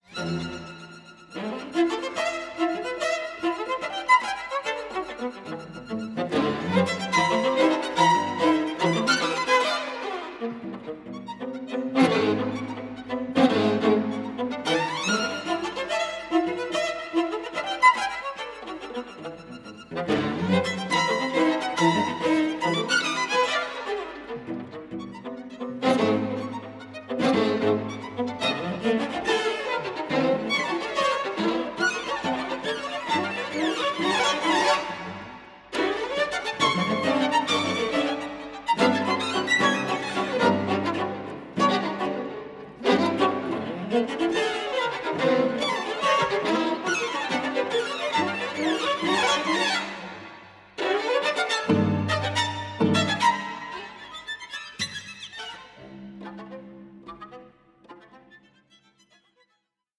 48/24 PCM Stereo  10,99 Select
AVANTGARDE CLASSICS FOR STRING QUARTET